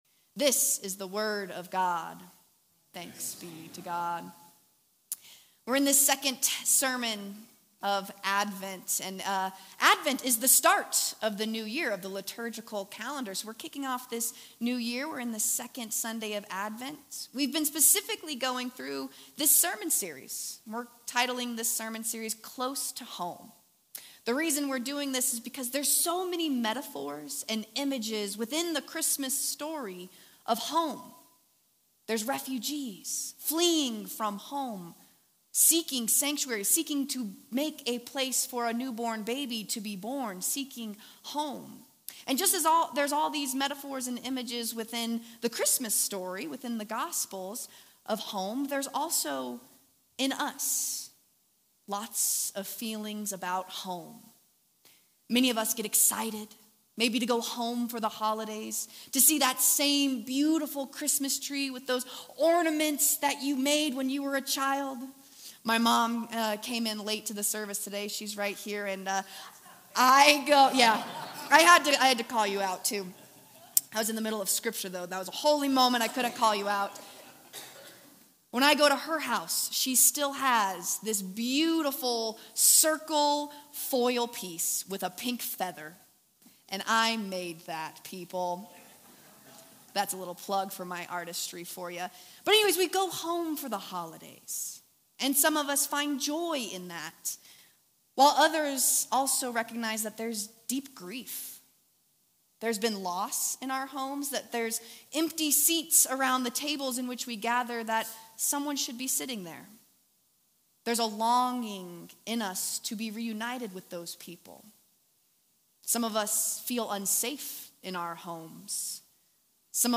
Full service.